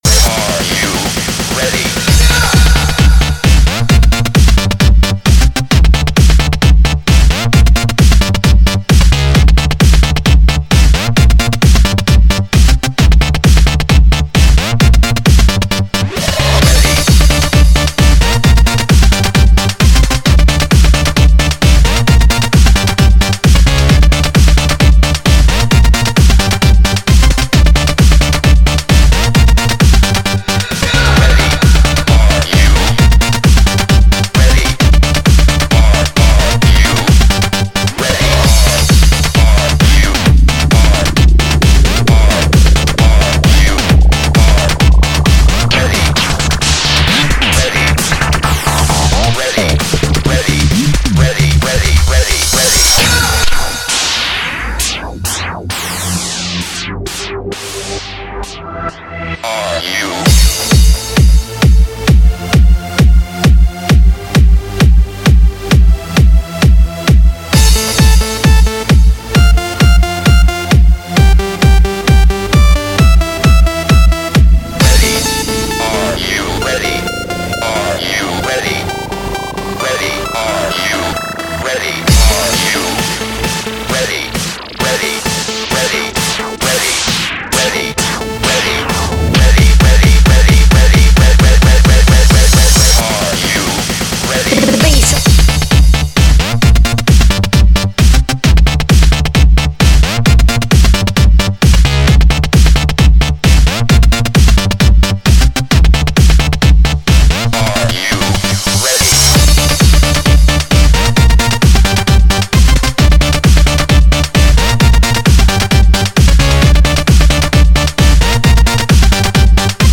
Жанр: Сlub